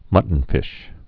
(mŭtn-fĭsh)